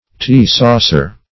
Tea-saucer \Tea"-sau`cer\, n. A small saucer in which a teacup is set.